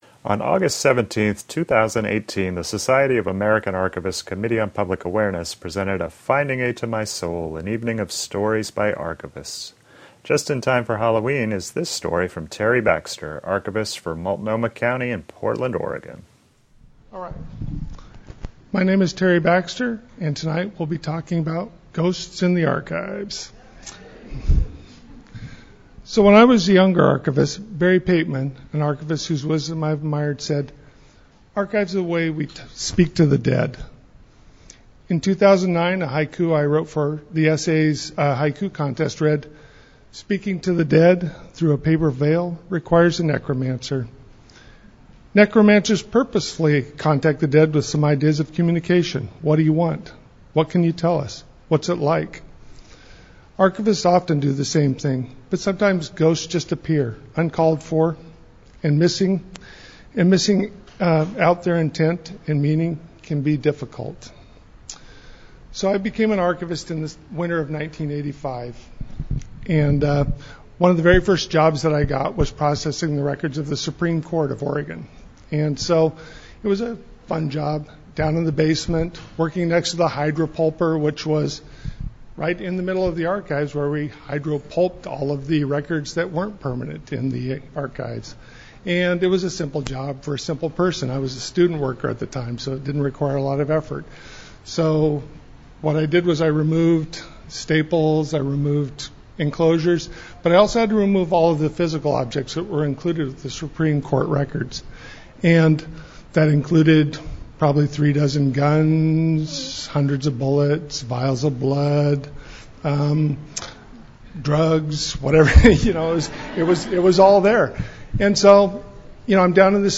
The event was a smashing success, with twelve archivists sharing stories to a packed room that were sad, funny, profound, and even a little scary at times.